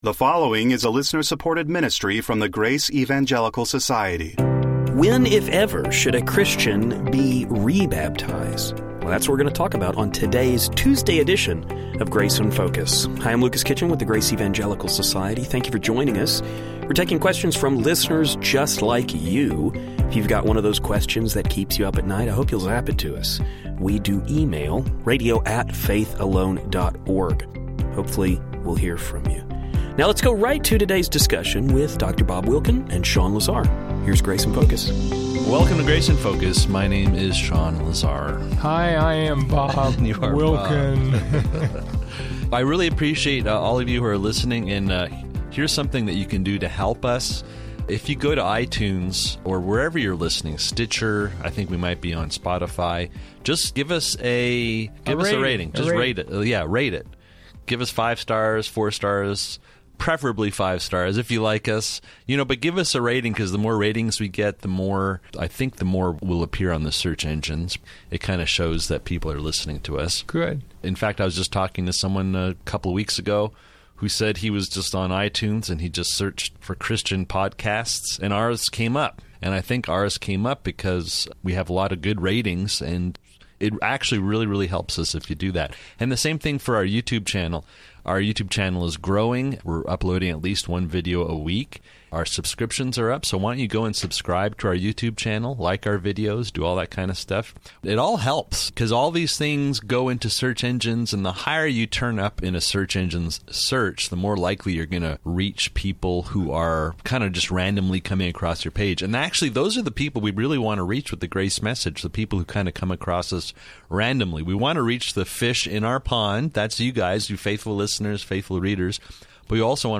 We will also hear the guys discuss this question in light of church history.